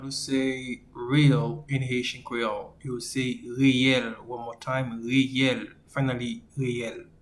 Pronunciation:
Real-in-Haitian-Creole-Reyel.mp3